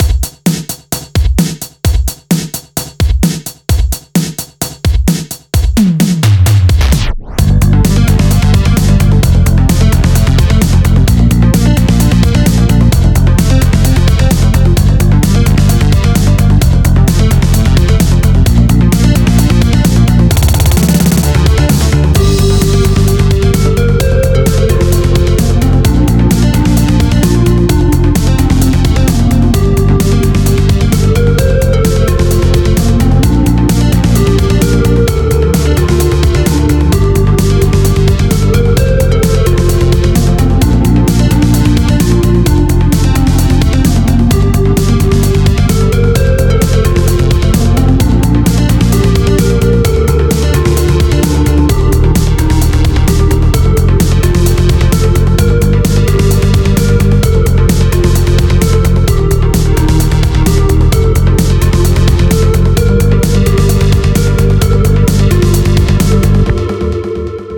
It doesn't sound too repetitive, nor does it sound premade. If anything it sounds kind of dynamic in some ways. The whole track is very bass heavy. It's so bass heavy it's to the point where it's kind of clipping. The melody is nice here though, that is a sick synth, and i dig the changes in the 2nd half. The track itself is actually pretty fire, it's just unfortunate the bass is way overpowering for my ears, it makes it sound quite compressed as a result.